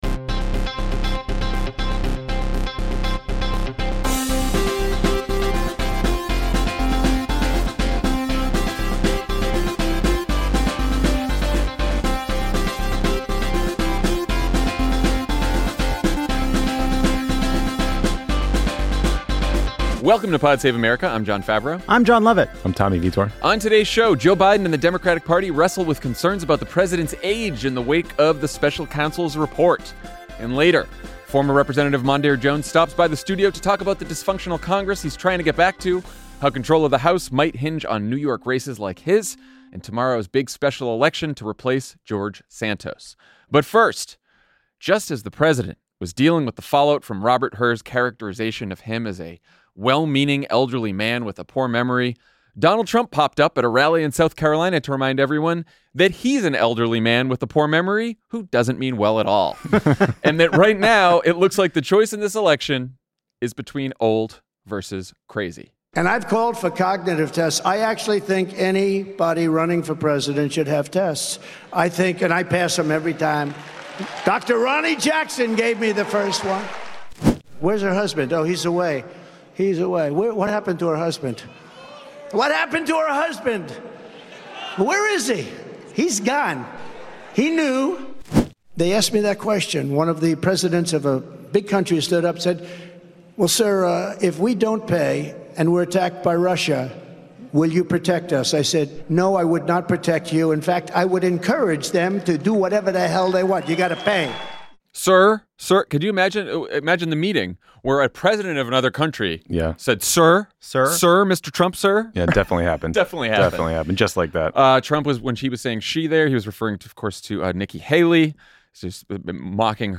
And later, former Representative Mondaire Jones stops by the studio to talk about disfunction in Congress, how control of the House might hinge on New York races like his, and today's big special election to replace George Santos.